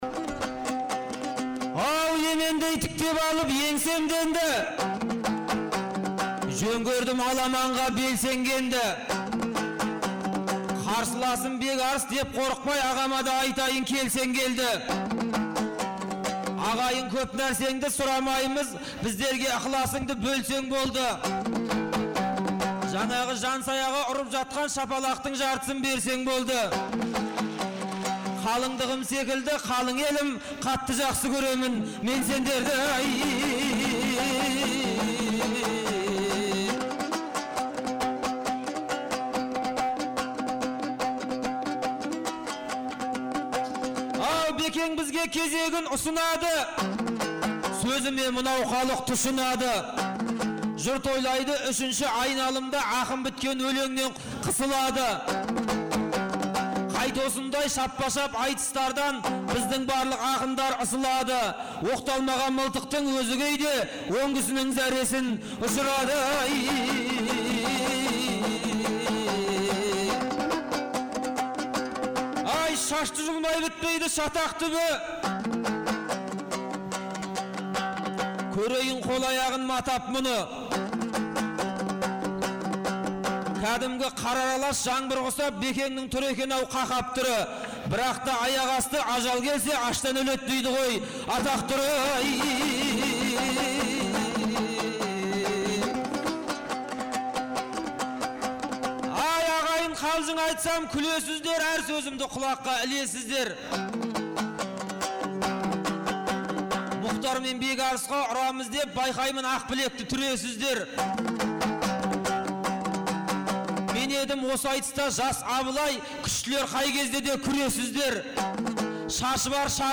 Наурыздың 15-16-сы күндері Шымкент қаласында екі күнге созылған республикалық «Наурыз» айтысы өтті. 2004 жылдан бері тұрақты өтіп келе жатқан айтыс биыл Төле бидің 350 және Абылай ханның 300 жылдықтарына арналды.